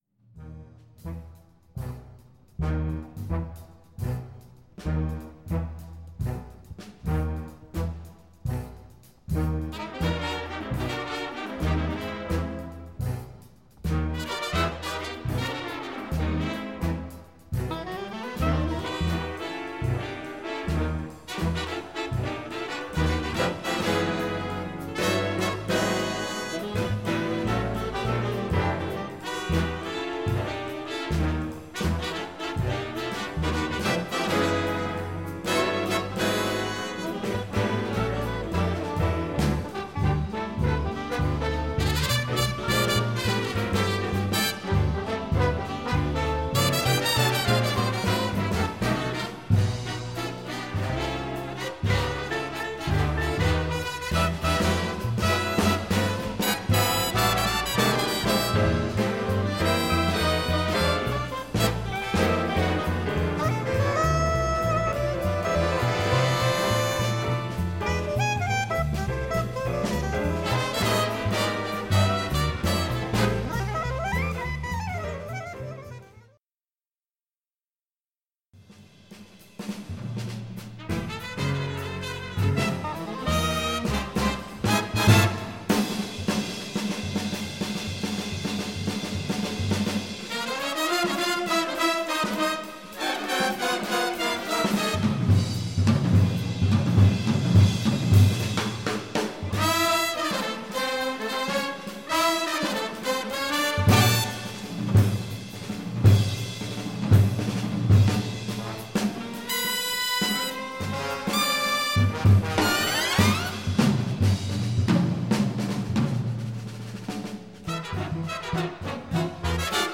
soprano sax
Most of the audience went wild, and some walked out.